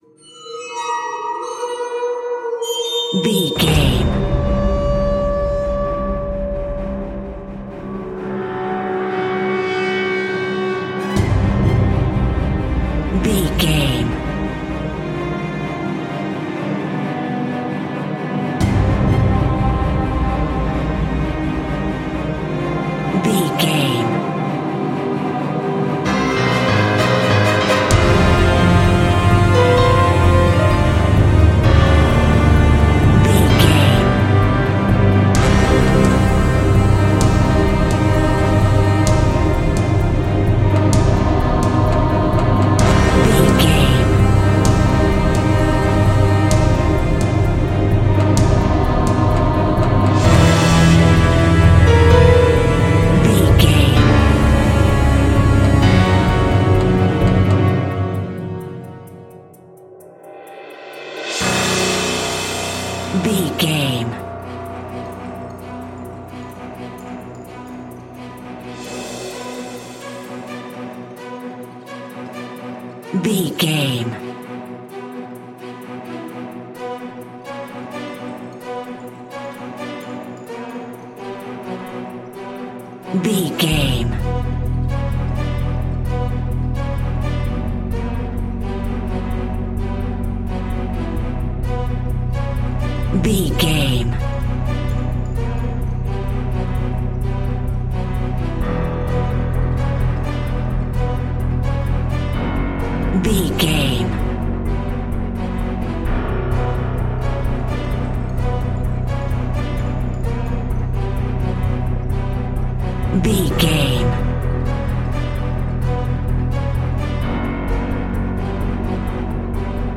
In-crescendo
Thriller
Aeolian/Minor
scary
tension
ominous
dark
dramatic
strings
brass
drums
percussion
piano
orchestra
cinematic
orchestral
dynamic
fast paced
cymbals
gongs
viola
french horn trumpet
taiko drums
timpani